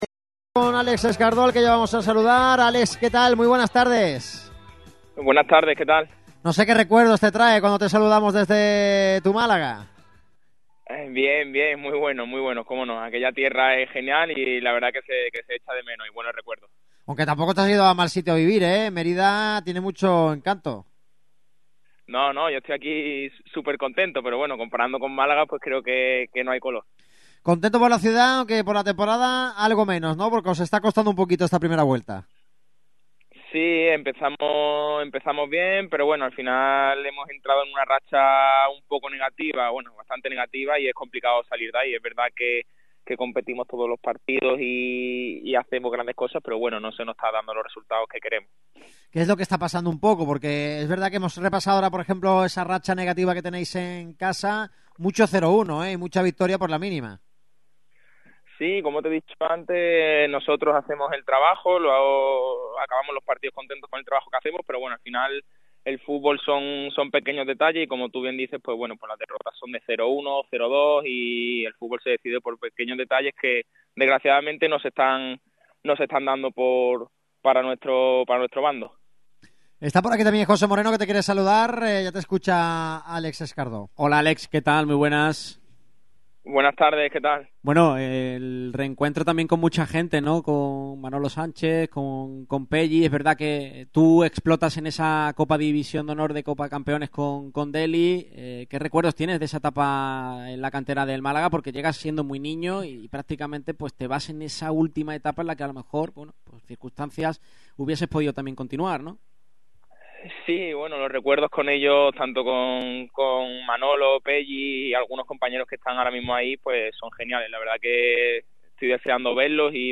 El extremo malagueño ha pasado por el micrófono rojo de Radio MARCA Málaga con motivo de la previa del partido de este domingo que enfrentará al Mérida contra el Málaga.